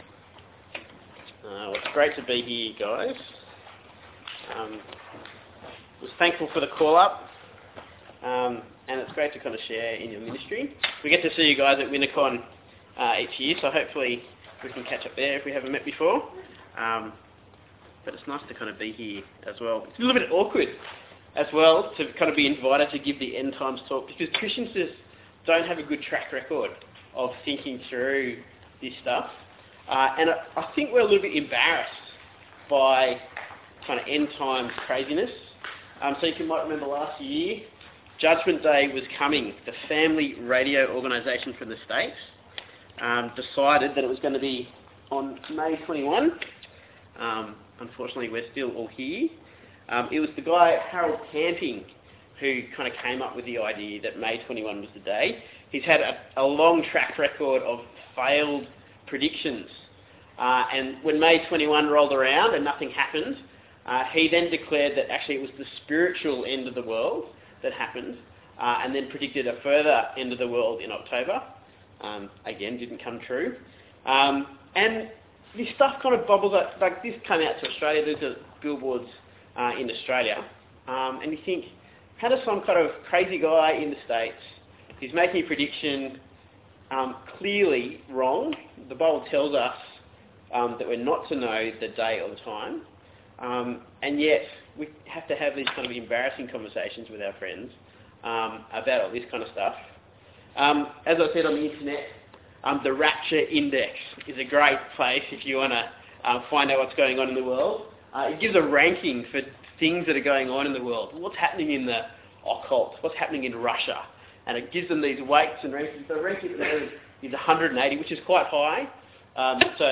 Passage: 2 Thessalonians 1:1-12 Talk Type: Bible Talk